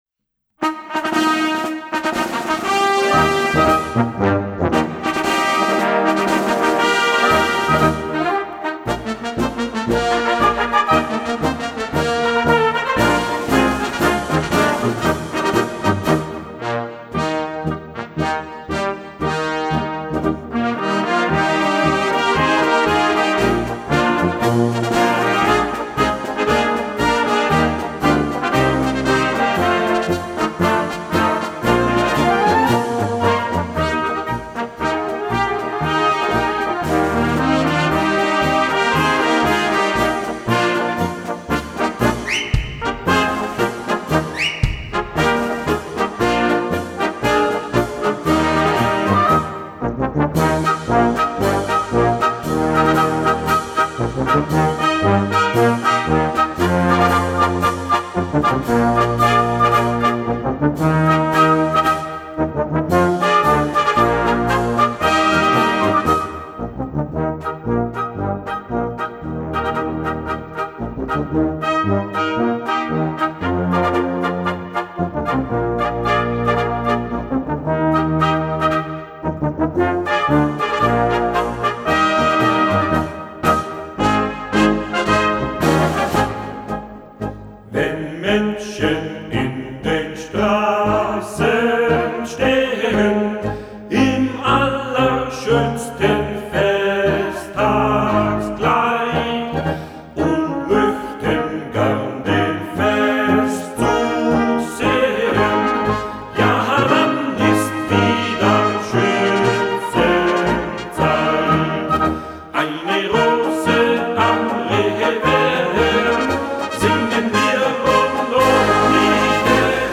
Orchester und Spielmannszug
Die Kleine Schützenmusik – Blasorchester und Spielmannszug – umfasst derzeit 105 aktive Musikerinnen und Musiker.
Der Marsch der “Kleinen Schützenmusik” kann im Trio gesungen werden.
biberacher_schtzenmarsch_gesang_1.mp3